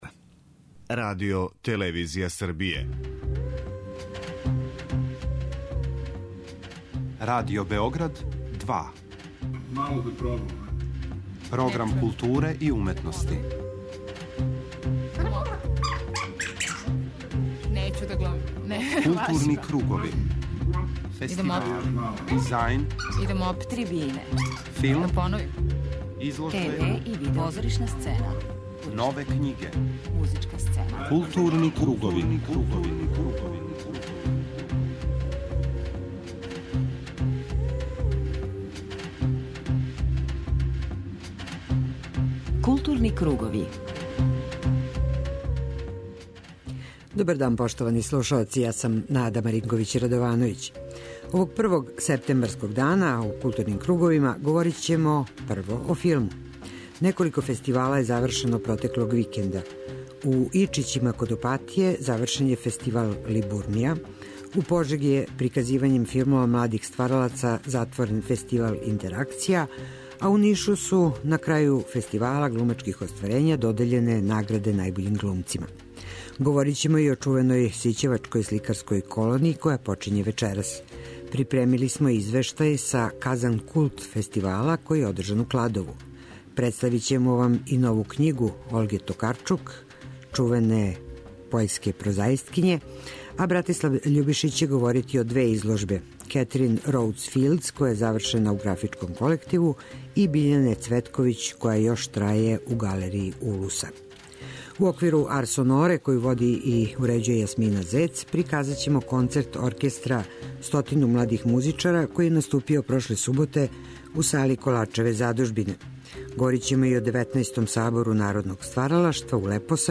У тематском блоку Арс сонорa приказаћемо концерт Оркестра стотину младих у којем свирају ученици музичких школа из Италије, Аустрије, Словеније, Србије и Македоније. Концерт је одржан 29. августа у сали Коларчеве задужбине. Емитоваћемо репортажу снимљену у Лепосавићу на Фестивалу народне музике, а слушаоци ће сазнати и који су концерти обележили Џез фестивал у Салфелдену.